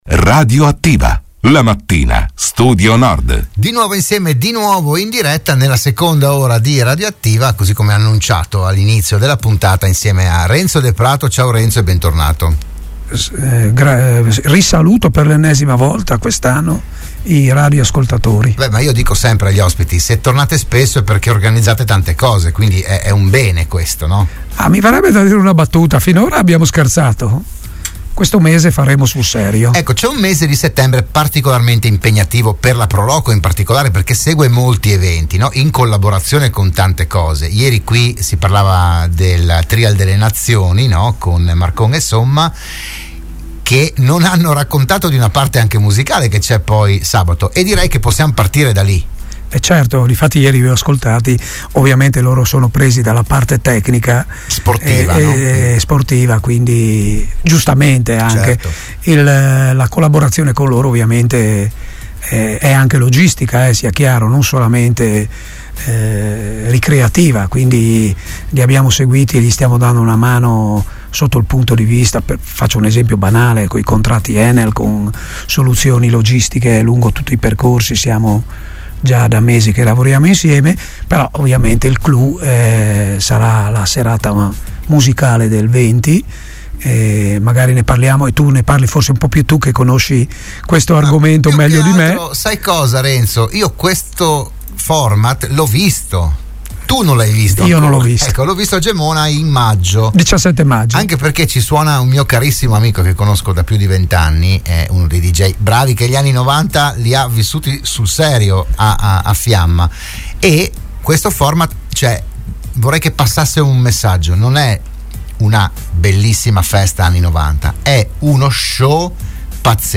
la trasmissione di Radio Studio Nord